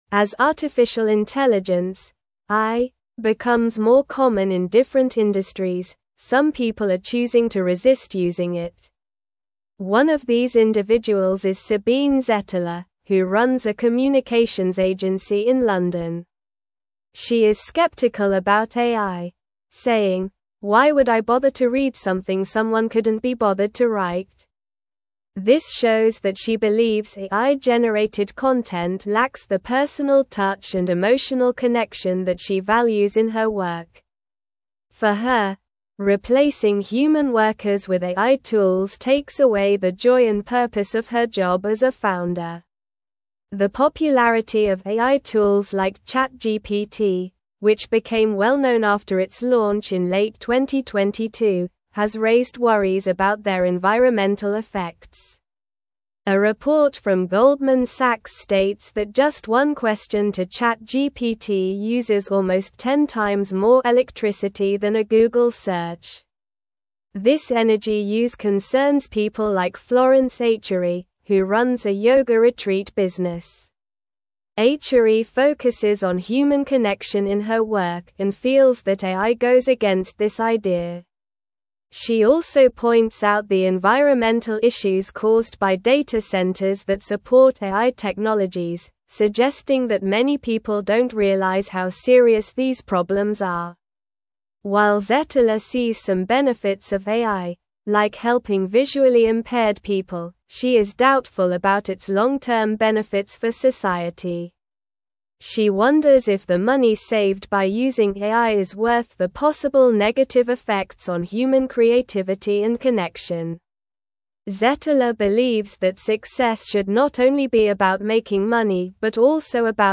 Slow
English-Newsroom-Upper-Intermediate-SLOW-Reading-Resistance-and-Acceptance-of-AI-in-Creative-Industries.mp3